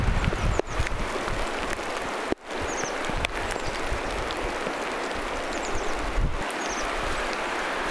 tässä on tilhilajin ääniä,
jotka eivät ole tavallisen tilhen ääniä
Ehkä amerikantilhi?
joku_tilhilaji_ilmeisesti_saaressa_ei_nakohavaintoa_aani_kuului_toisen_ruokinnan_luona.mp3